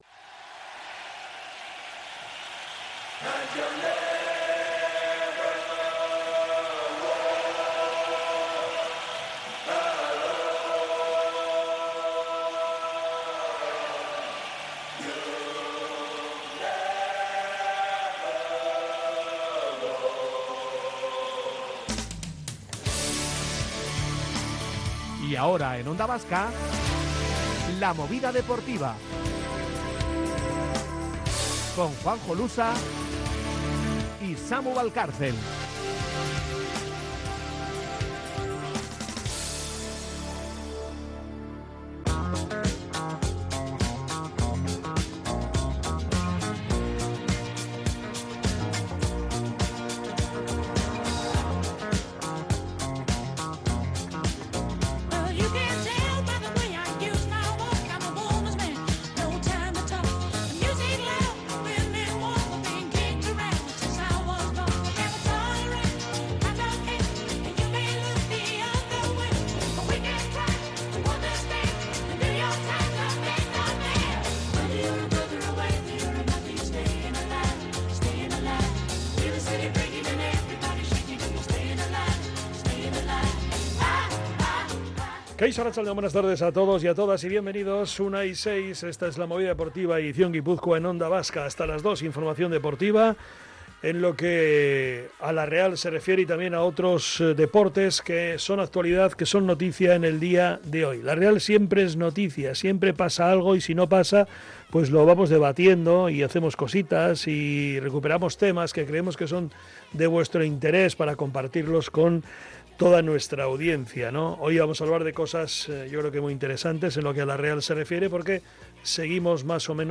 Así hemos narrado los goles de Oskarsson en el Real 2-0 PAOK - Onda Vasca